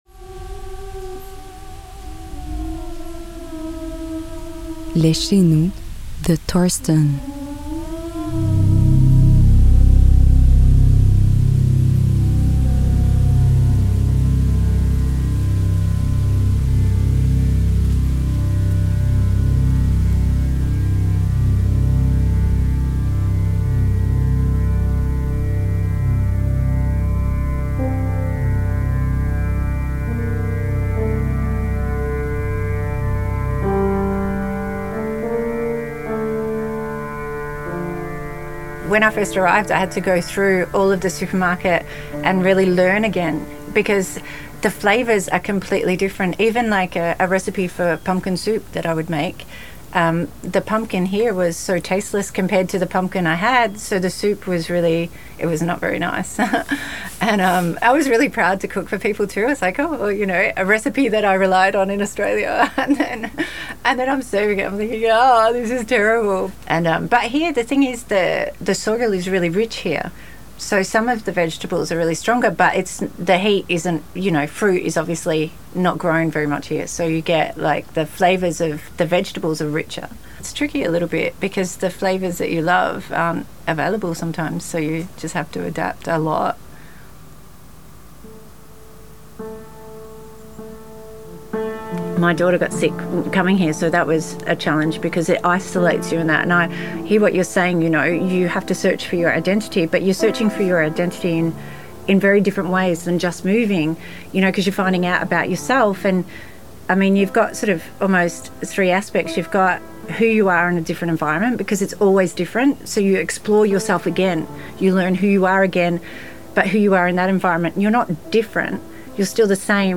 2022, 10:11 min., microhistoire de DEVENIR CHEZ-NOUS, s.-t. ang, couleur, 16 :9, HDSR 23.976, stéréo
En conversation avec